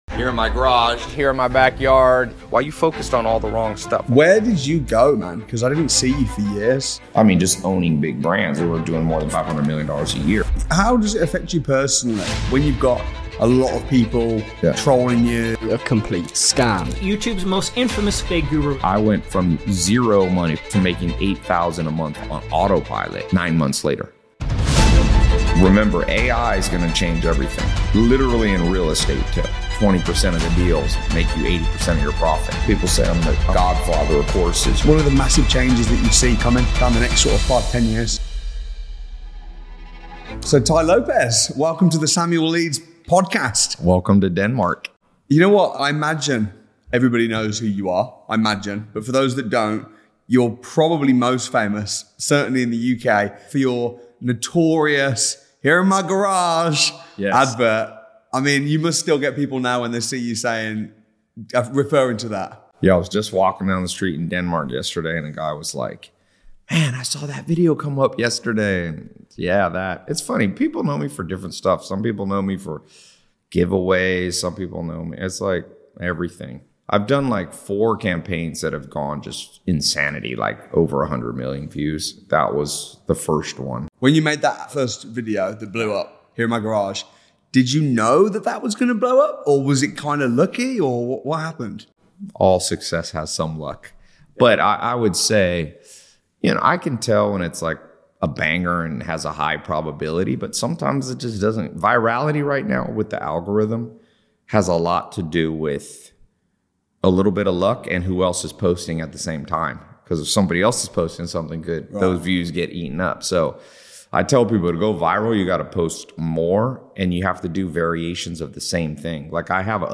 'AI Will Change Real Estate' | Interview with Tai Lopez